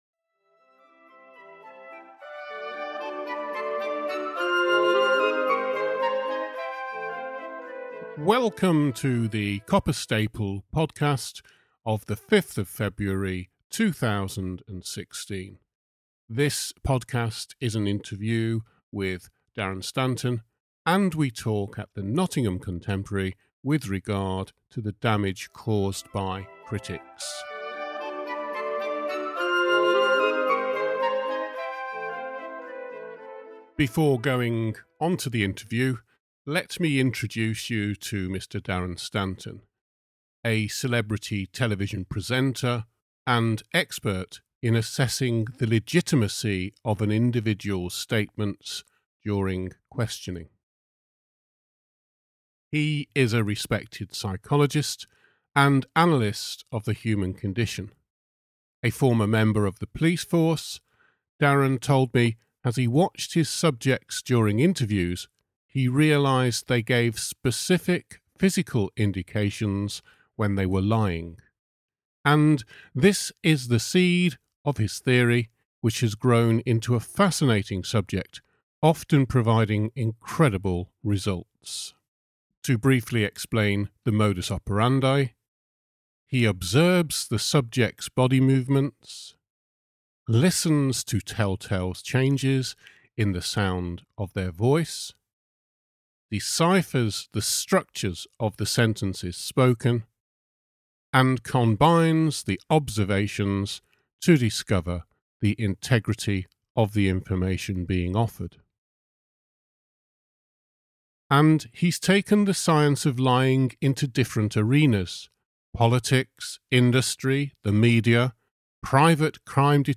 This interview will be of benefit to any one who becomes or is subject to the effects of the critic.